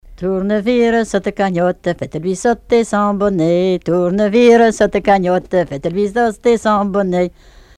danse : branle
collecte en Vendée
Répertoire de chants brefs et traditionnels
Pièce musicale inédite